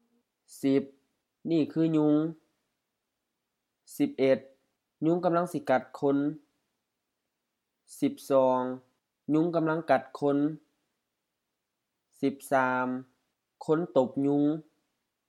ญุง ɲuŋ HR ยุง mosquito
กัด gat M กัด to bite
คน khon HR คน person, people